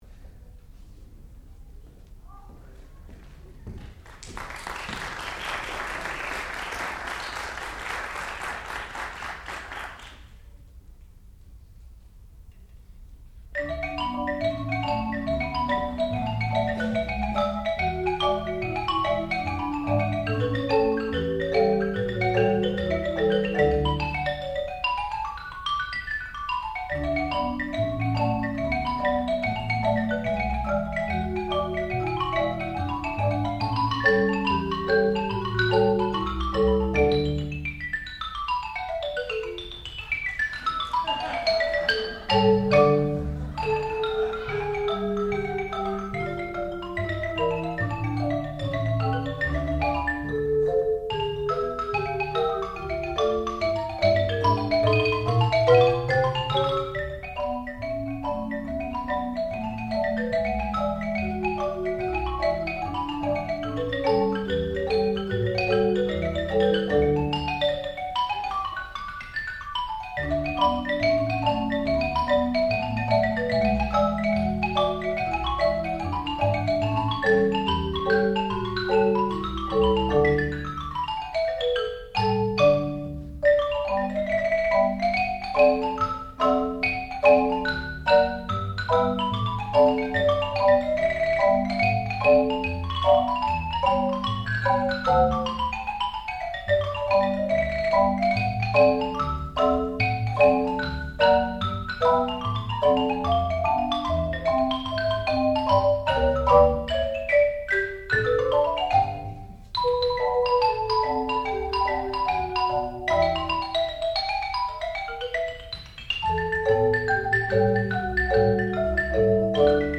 sound recording-musical
classical music
percussion